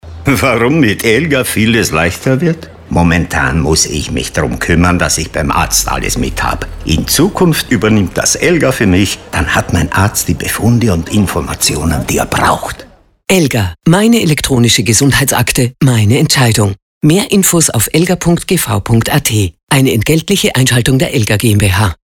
ELGA-Radiospot.mp3